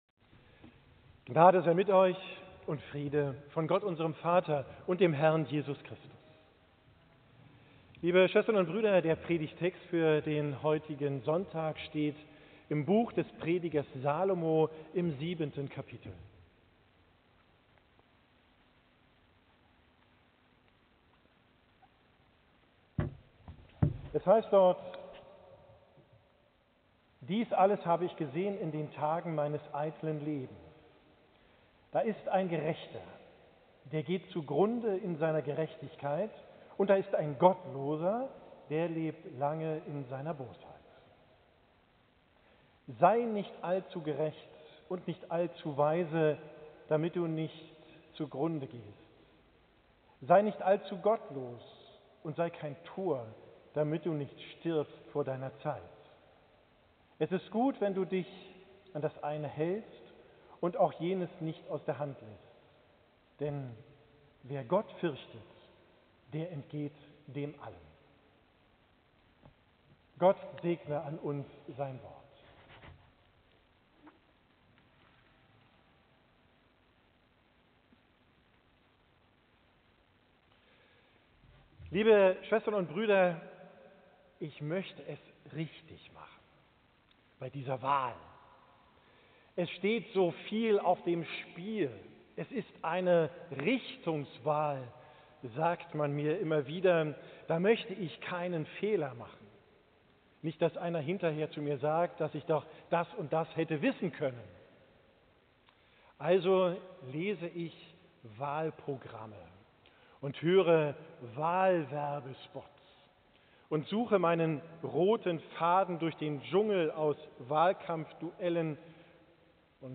Preidgt vom Sonntag Septuagesimae, 16.